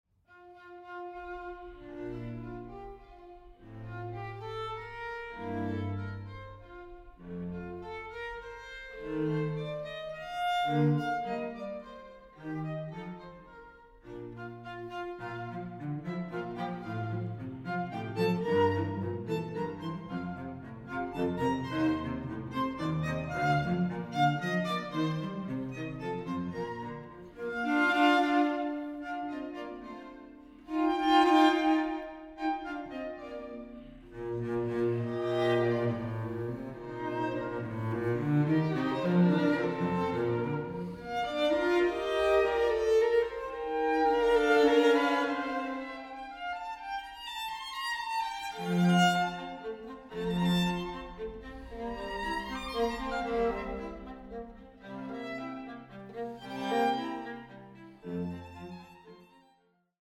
Aufnahme: Ölbergkirche, Berlin, 2020